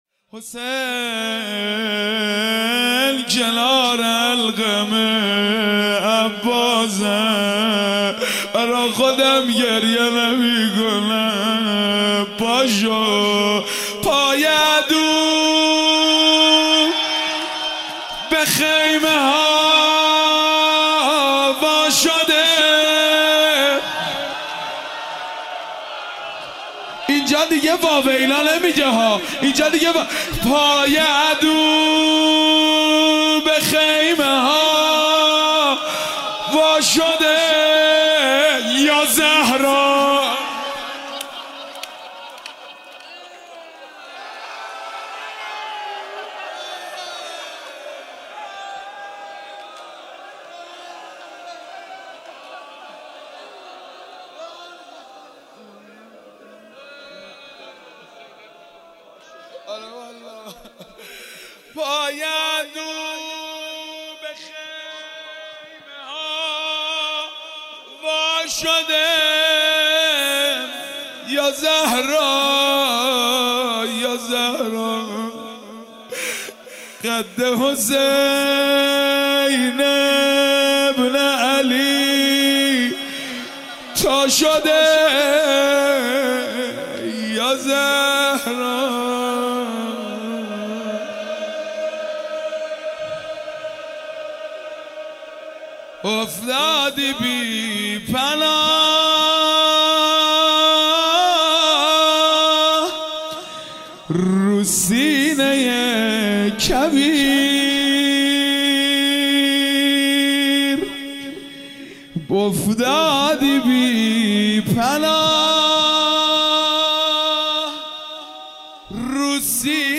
01-Rozeh-1.mp3